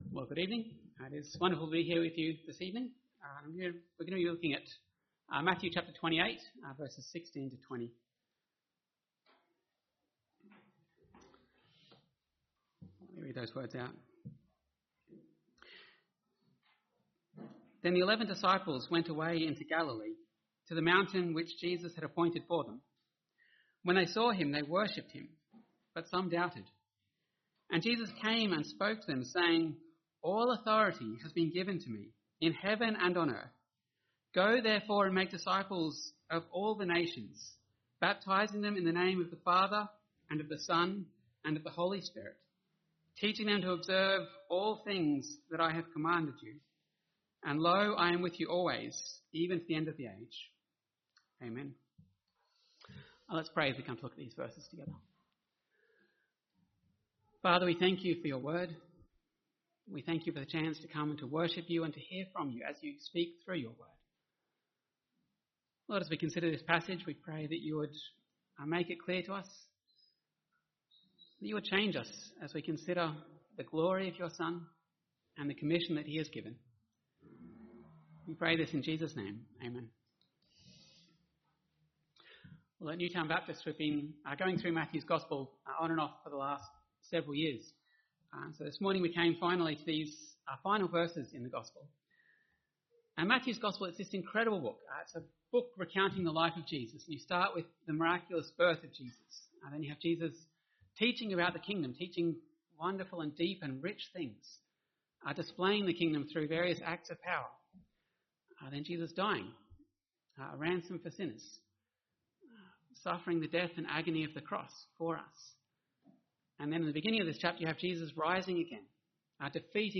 Sermons , Visiting Speakers